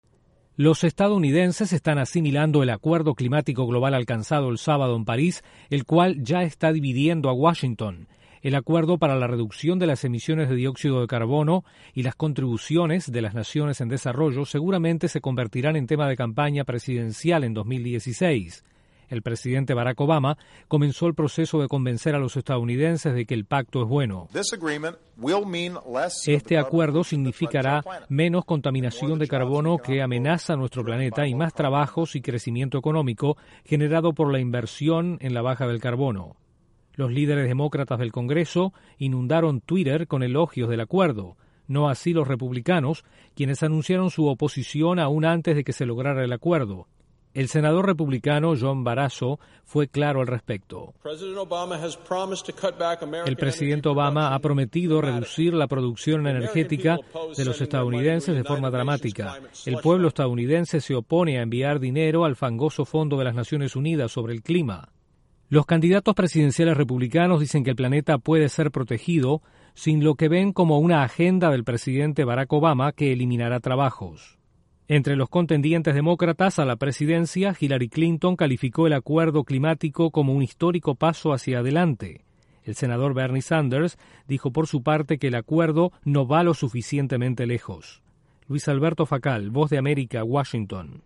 El acuerdo climático alcanzado en París genera reacciones mixtas en Estados Unidos. Desde la Voz de América en Washington informa